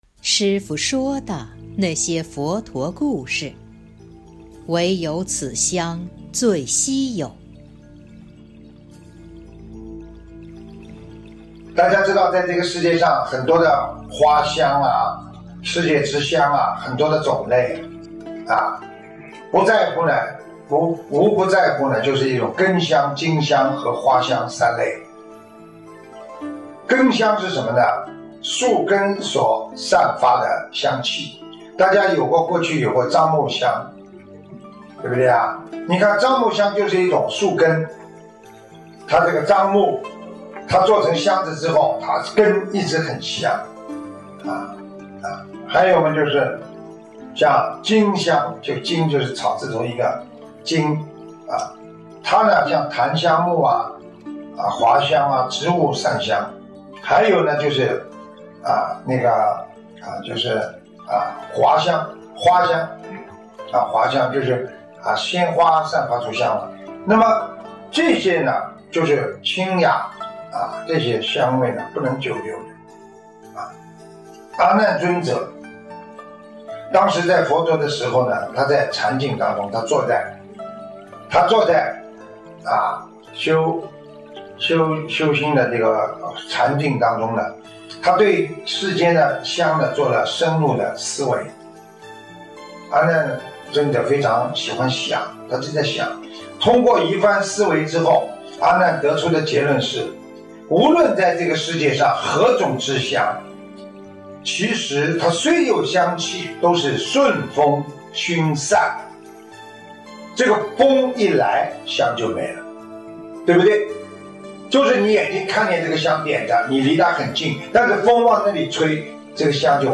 （后附师父解说）2020年02月05日【师父原声音】